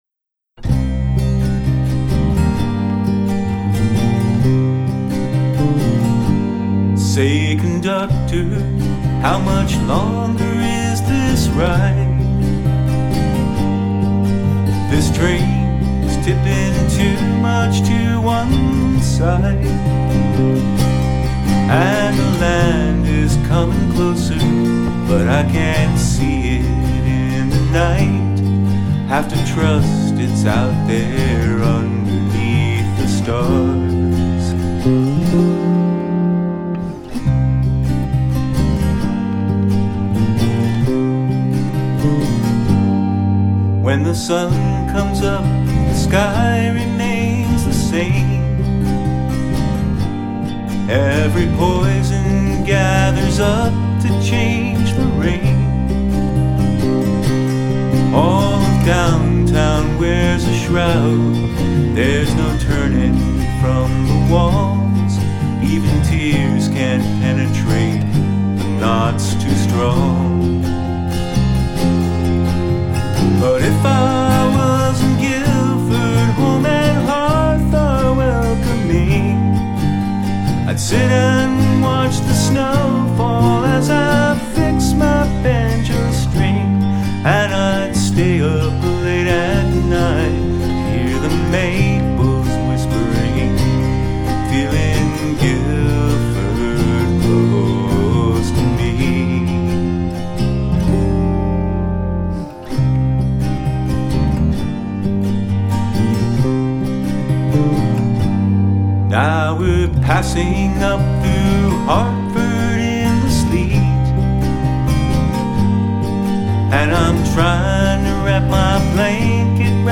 autoharps
and I was often playing guitar with a capo.